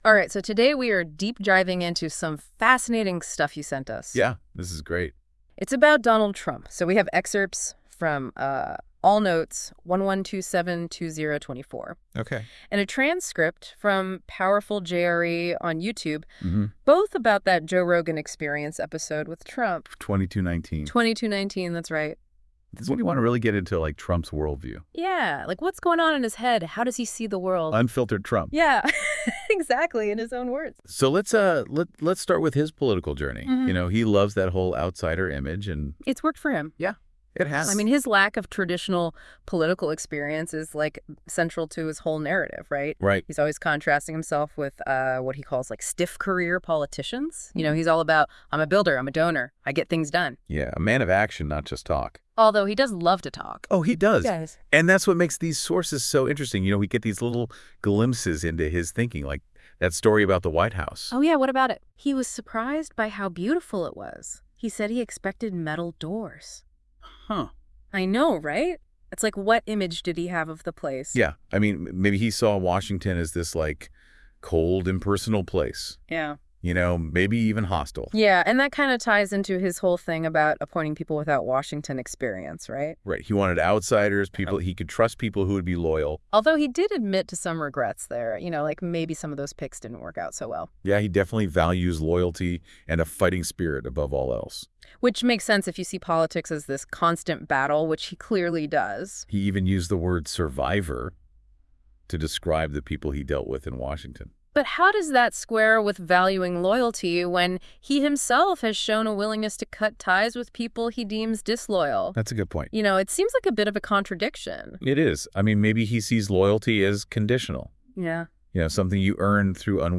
Listen to the Audio convo about the episode…